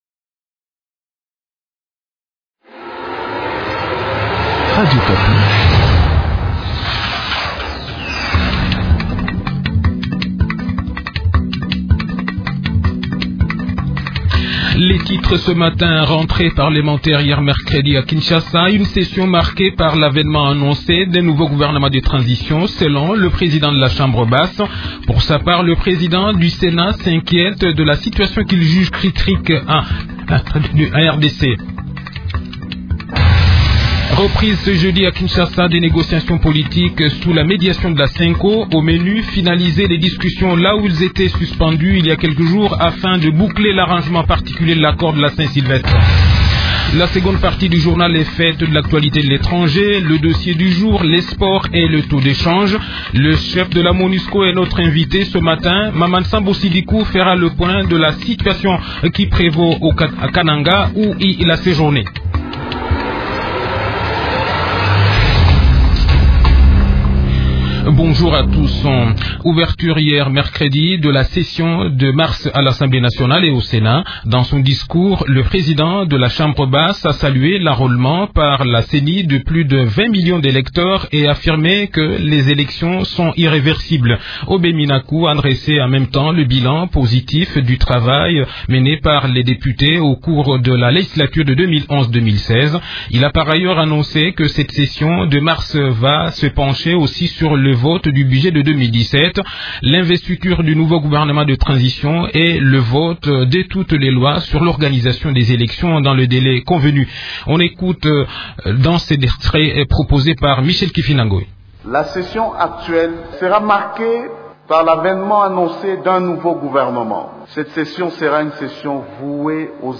Pour entendre l’intégralité des déclarations de Aubin Minaku, écoutez le journal parlé du 16 mars de Radio Okapi, en cliquant ici.